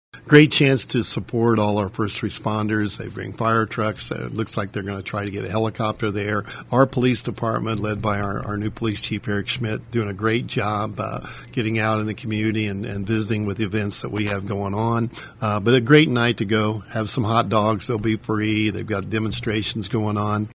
Robinson Mayor, Mike Shimer, says this is a great chance to support area first responders.